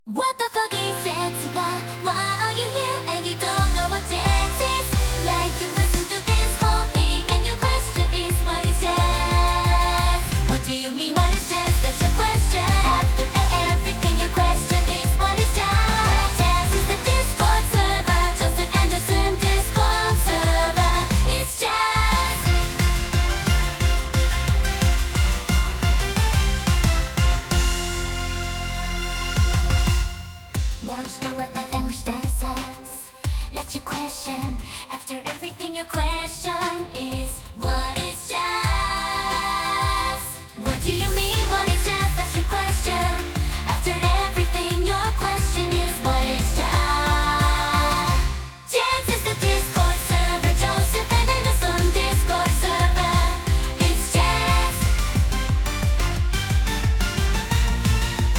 anisong